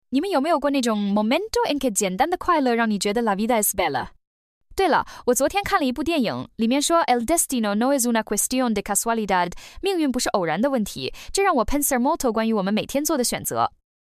给智能硬件们装上“AI声带”的Speech-02模型属于TTS（文本转语音）模型。
6826c4f124194_6826c4f12410a_中文、西班牙语、意大利语混说